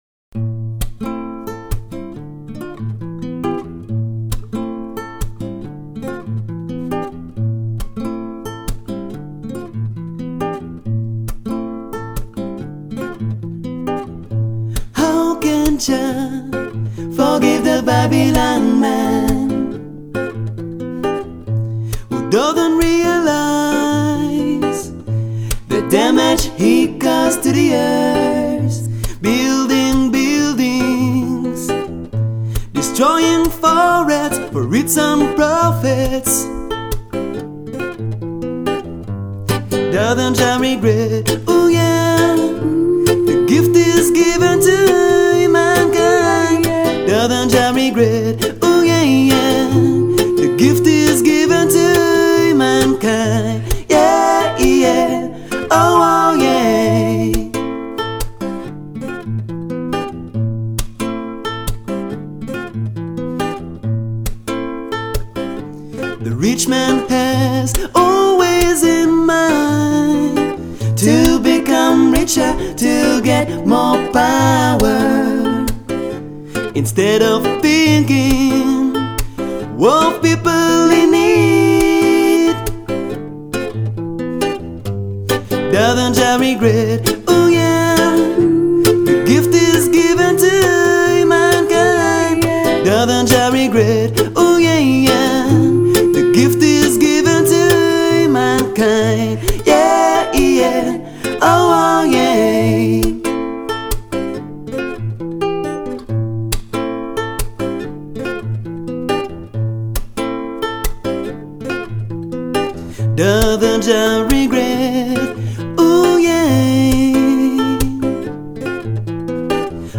• Genre: Acoustic / Reggae